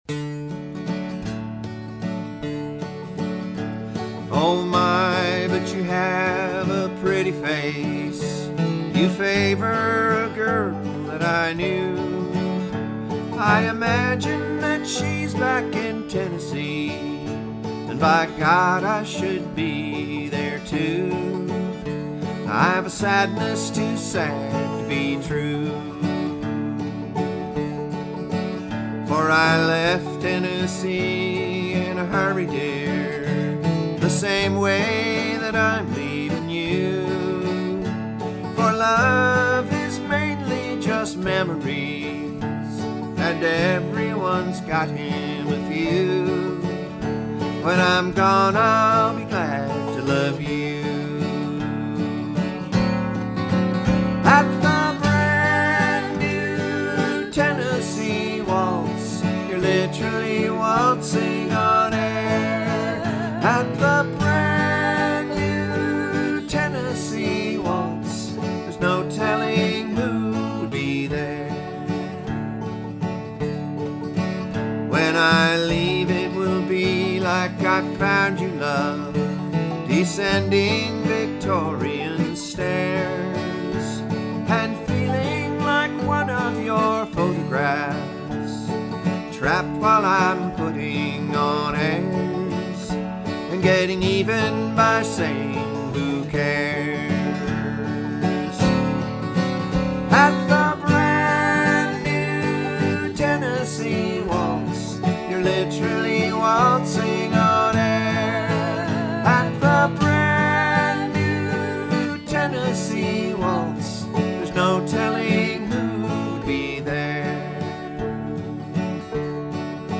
guitar/lead vocal
banjo